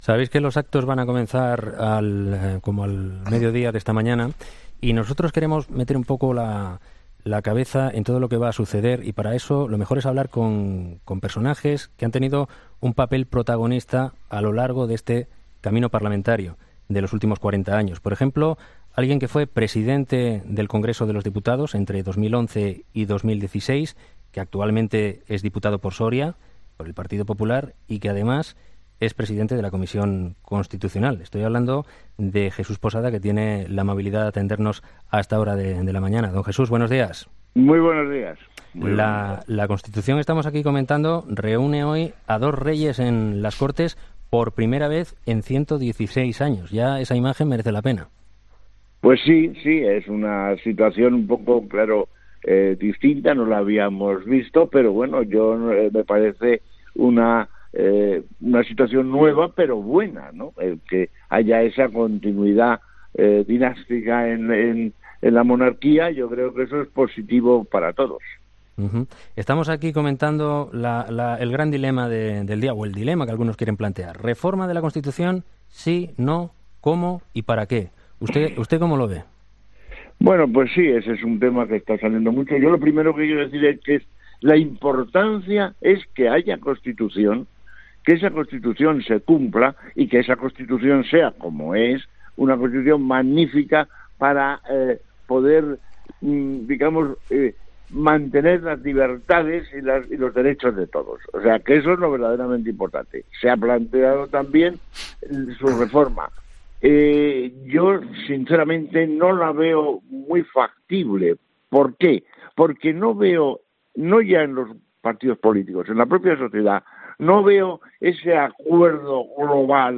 Entrevista a Jesús Posada en 'Hererra en COPE'